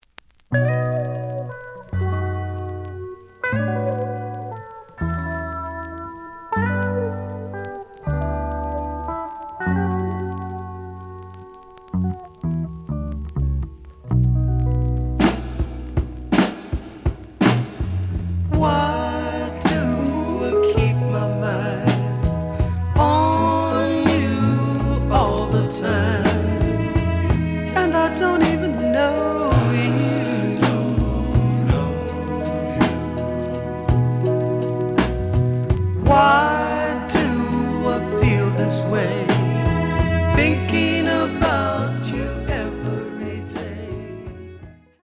The audio sample is recorded from the actual item.
●Genre: Soul, 70's Soul
Looks good, but slight noise on A side.)